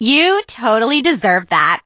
emz_kill_vo_04.ogg